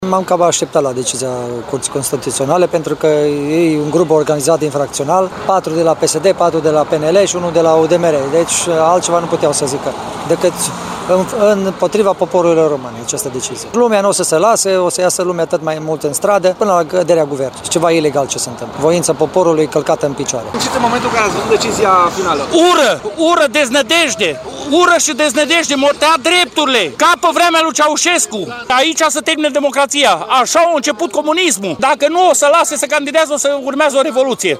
La Arad, protestatarii se declară dezamăgiți și triști și poartă steaguri, fără să scandeze.
02-vox-arad-20.mp3